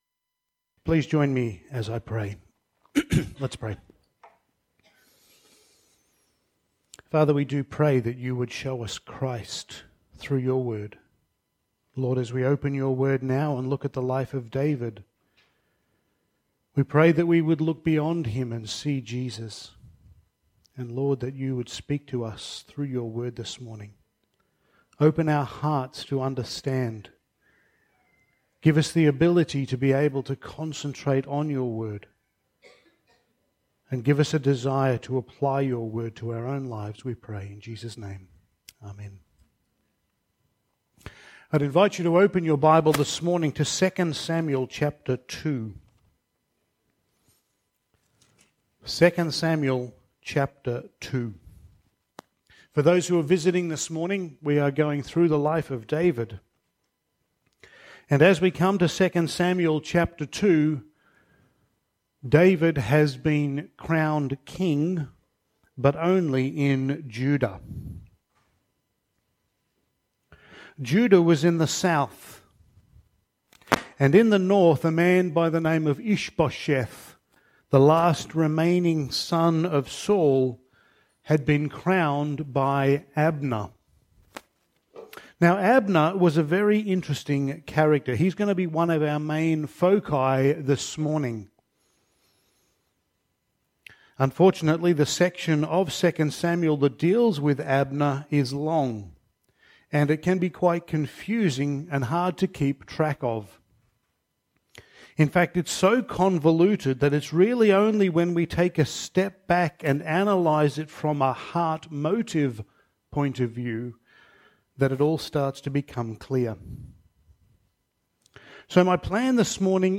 Passage: 2 Samuel 2:12-3:39 Service Type: Sunday Morning